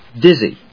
/dízi(米国英語), ˈdɪzi:(英国英語)/